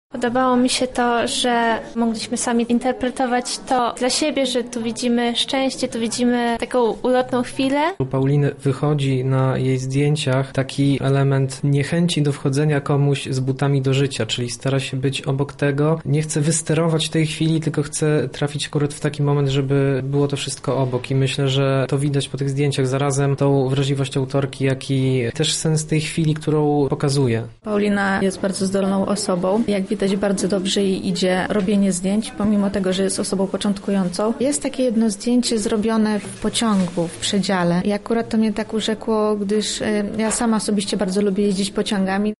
Za nami wernisaż fotografii ulicznej zatytułowany Niedopowiedzenia.
zapytał uczestników o wrażenia.